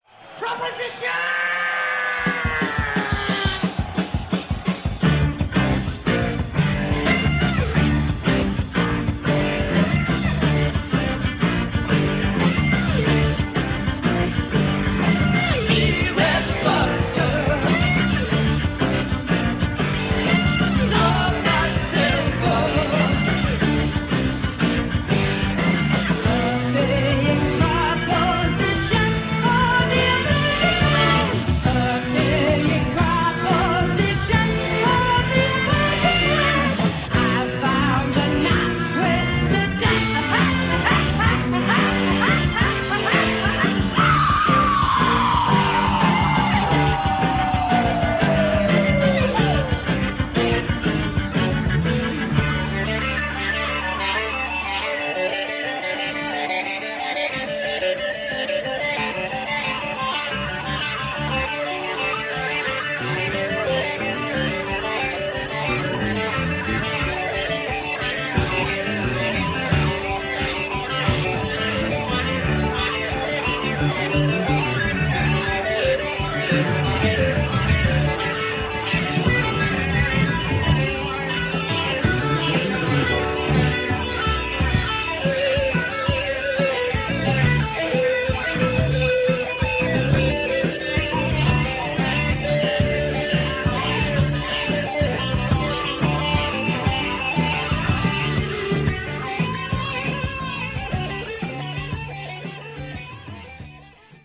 stereo, 4.0 Khz, 20 Kbps, file size: 403 Kb